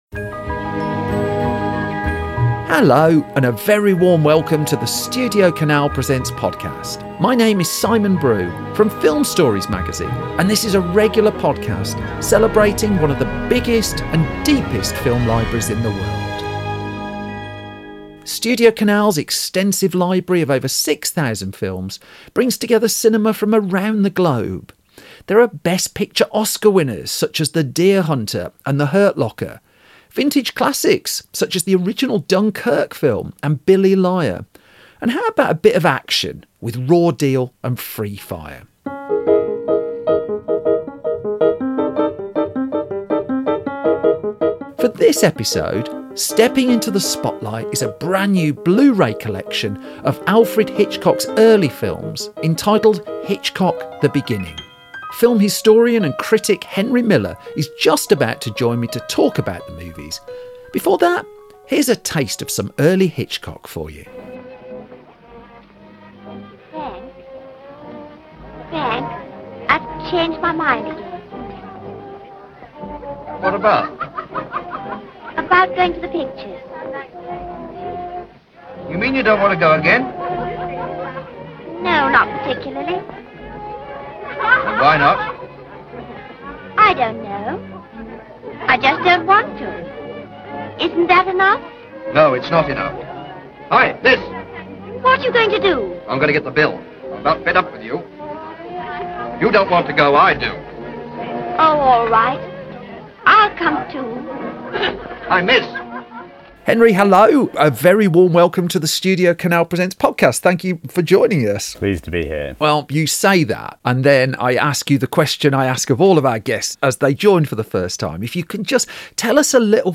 film historian and critic